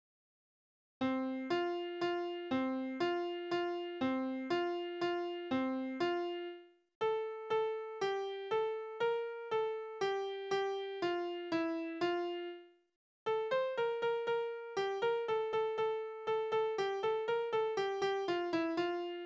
Tanzverse: Winkeltanz
Tonart: F-Dur
Taktart: 3/4
Tonumfang: Oktave
Besetzung: vokal